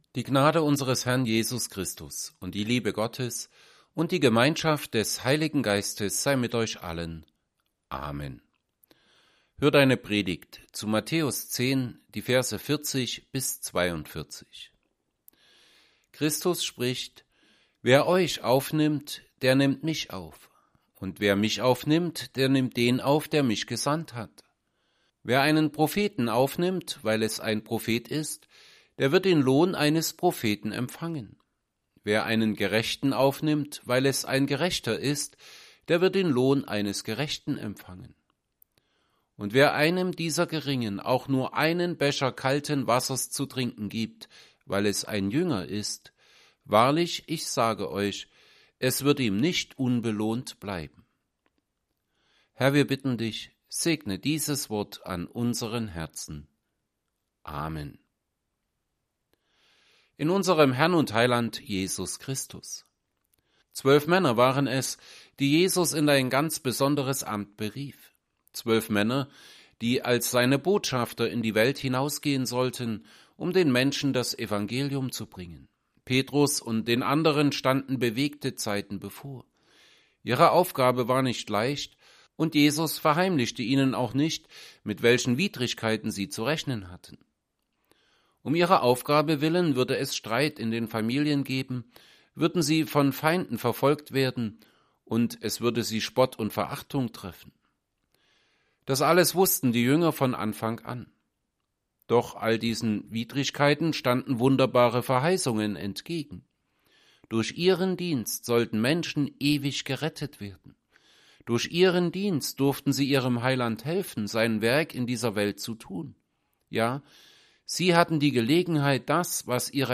Evangelienpredigten Passage: Matthew 10:40-42 Gottesdienst: Gottesdienst %todo_render% Dateien zum Herunterladen Notizen « 19.